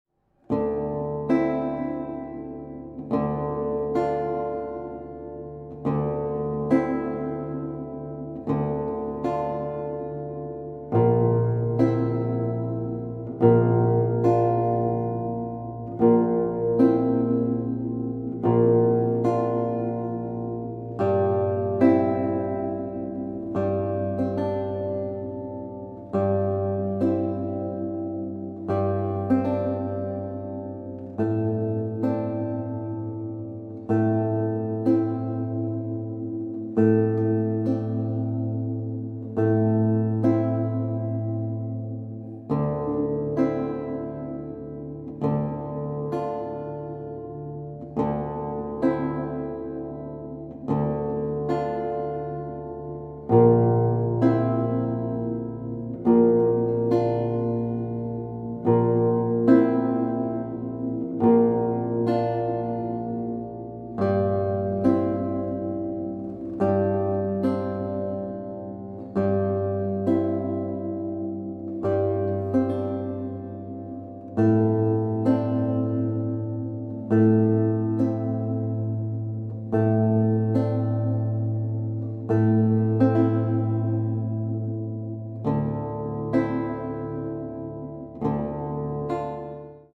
composer, lute & oud player from Japan
Lute